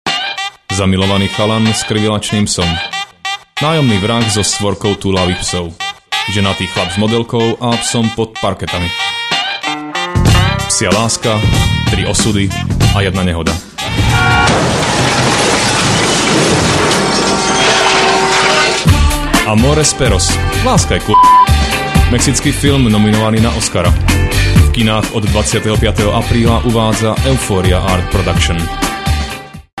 Rozhlasový spot (30 sec – 200 Kbytes)